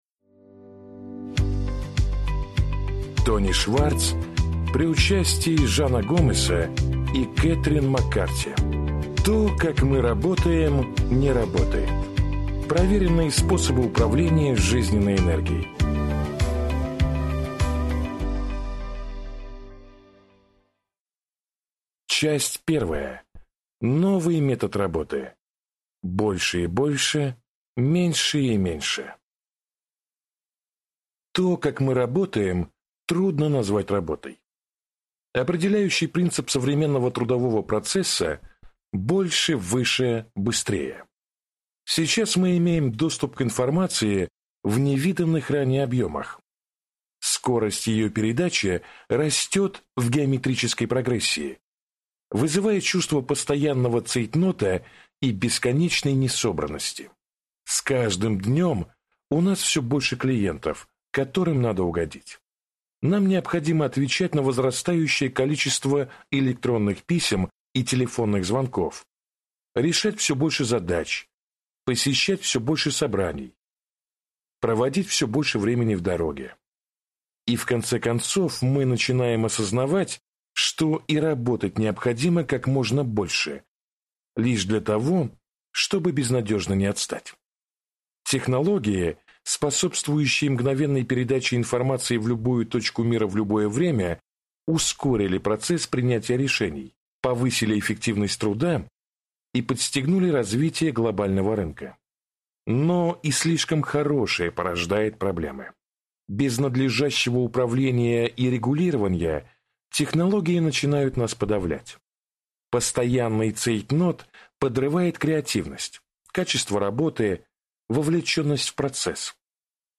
Аудиокнига То, как мы работаем, – не работает. Проверенные способы управления жизненной энергией | Библиотека аудиокниг